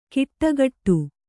♪ kiṭṭagaṭṭu